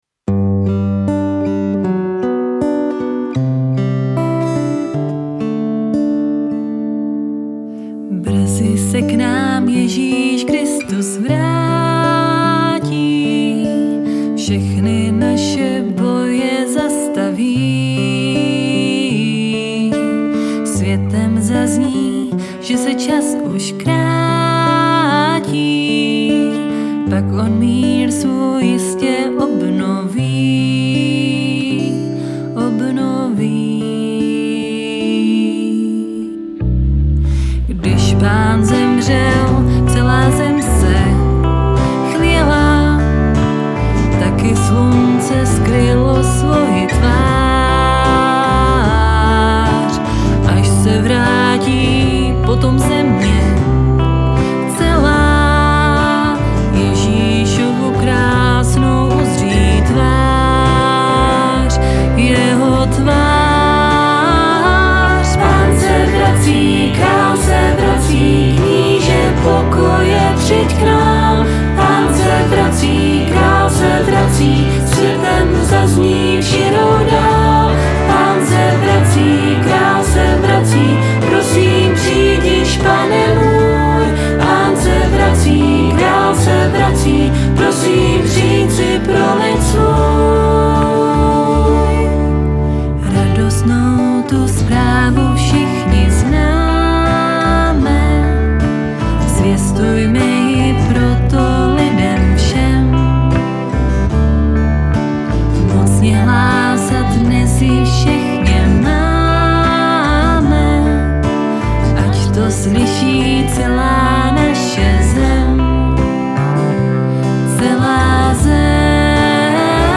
Míchačka
A ten druhej hlas ve sloce je už strašně utopenej.